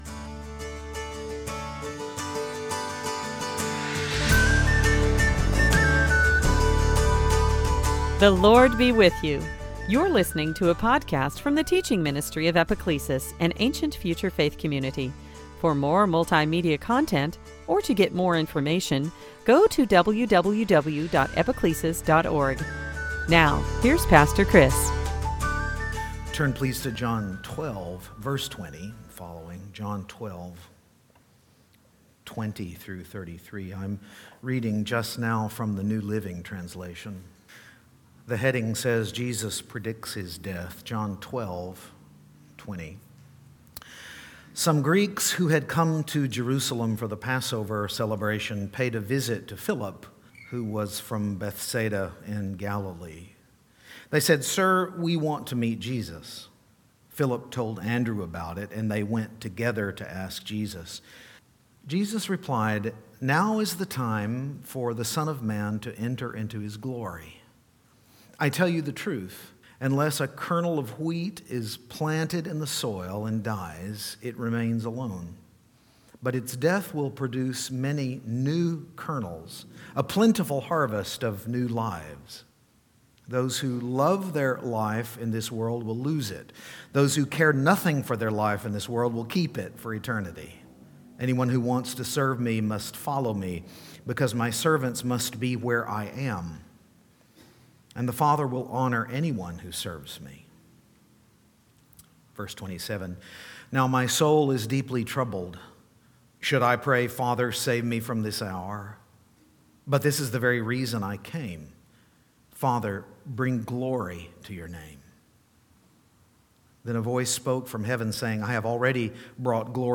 Sunday Teaching